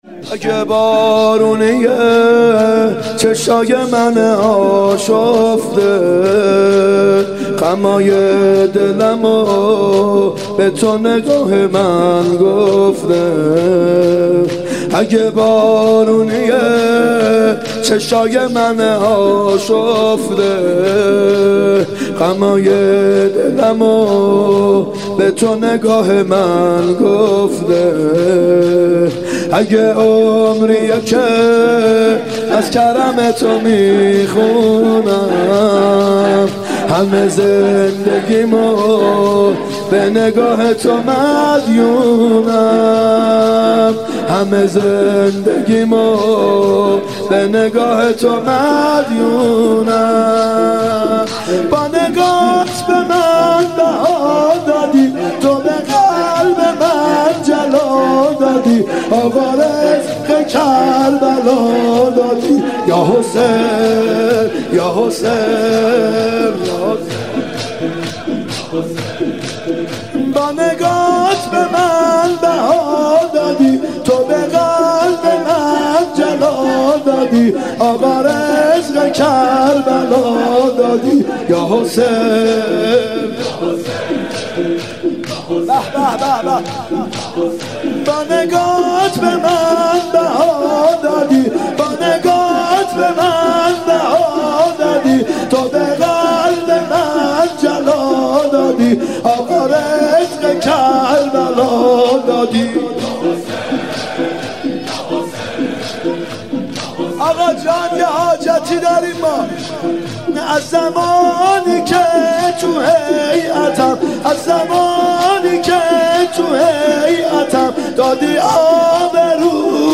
محرم 94 شب سوم شور (اگه بارونیه چشای منه اشفته
محرم 94(هیات یا مهدی عج)